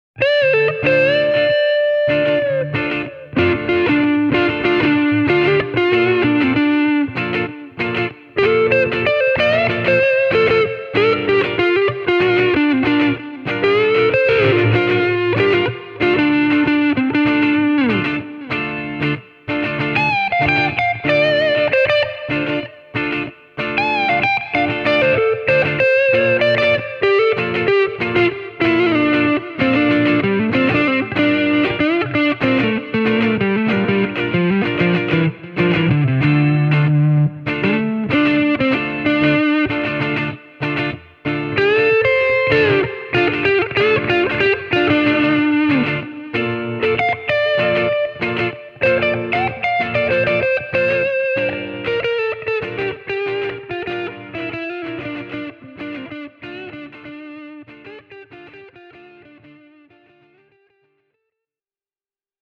Niissä on juuri oikea annos purevuutta, voimaa ja basson lämpöä, että ne pystyvät tyydyttämään P-90-soundin ystäviä. Kaula- ja tallamikin keskinäinen balanssi on myös todella hyvä.